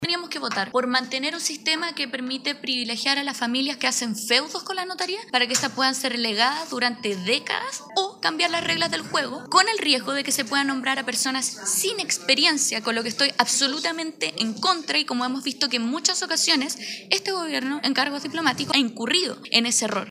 Con cuestionamientos, la diputada de la bancada independientes-PPD, Camila Musante, se abstuvo y calificó el debate como “podrido”.
cuna-comision-mixta-notarios-camila-musante.mp3